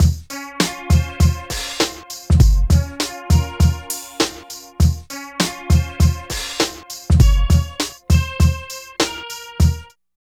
60 LOOP   -R.wav